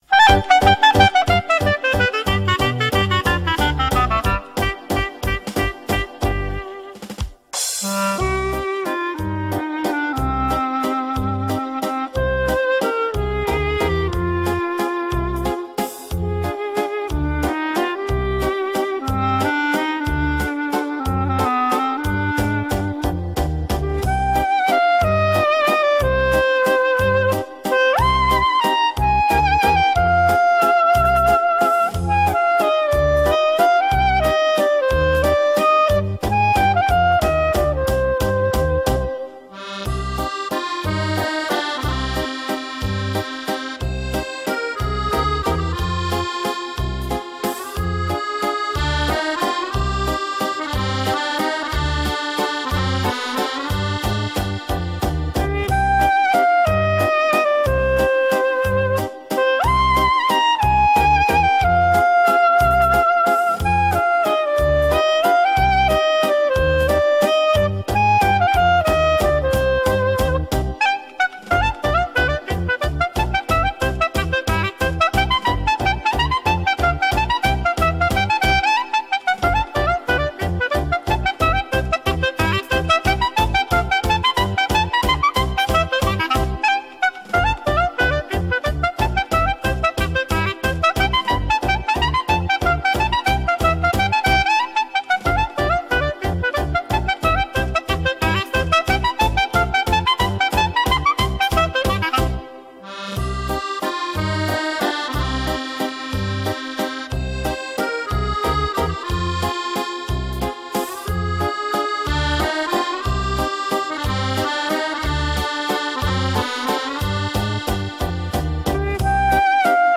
Кларнет и оркестр.